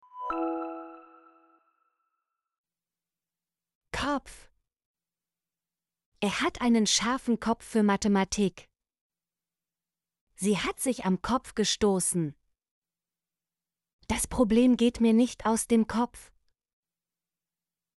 kopf - Example Sentences & Pronunciation, German Frequency List